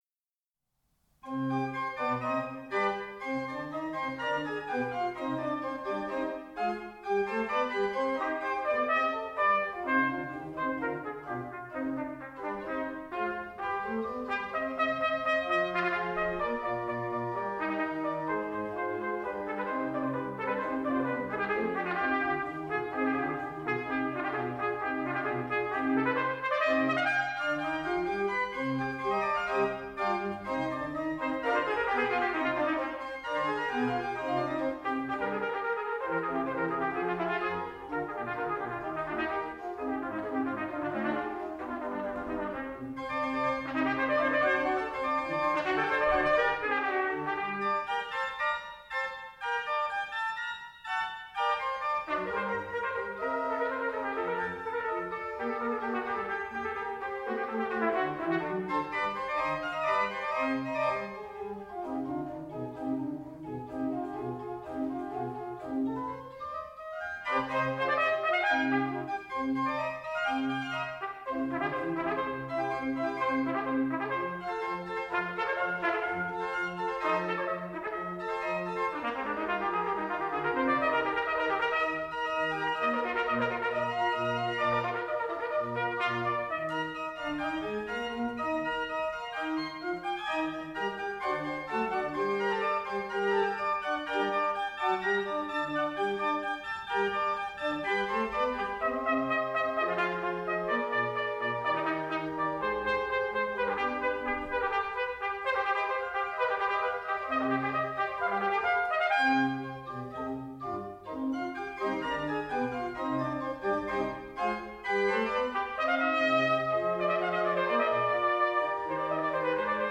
bearbeitet für Orgel und Trompete.